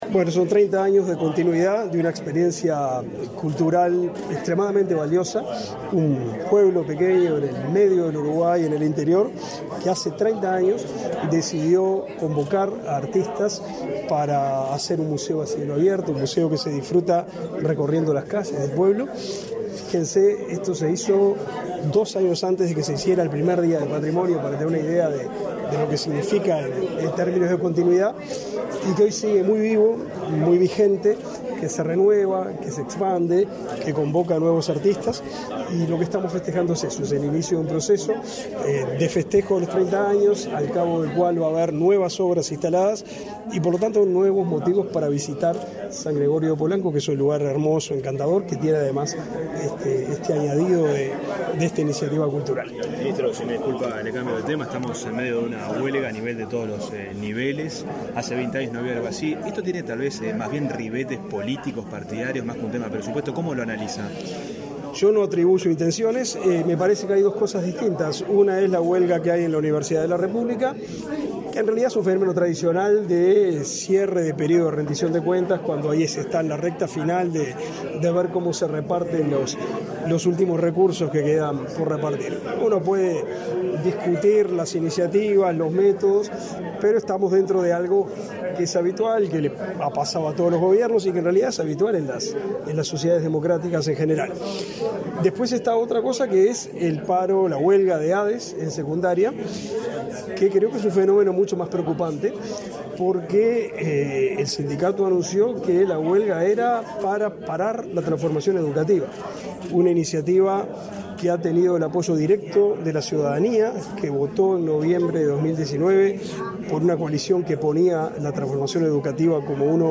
Declaraciones a la prensa del ministro de Educación y Cultura, Pablo da Silveira
Al finalizar el acto, realizó declaraciones a la prensa.